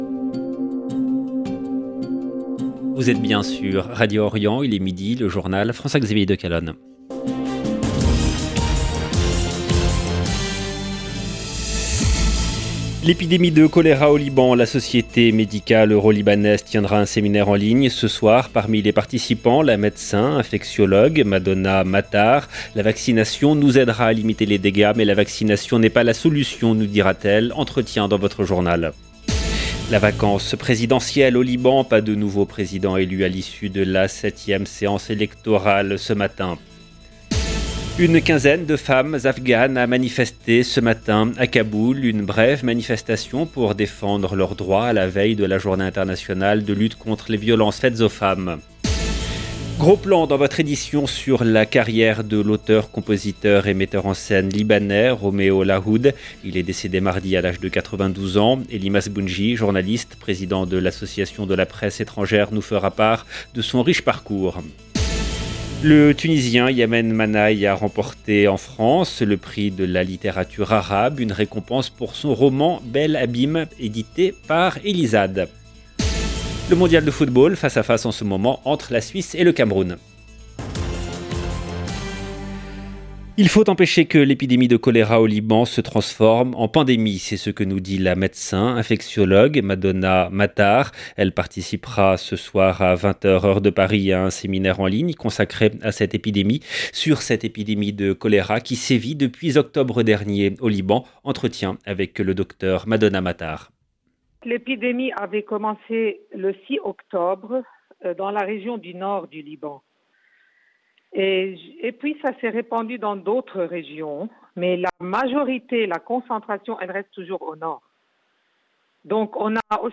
Entretien dans votre journal.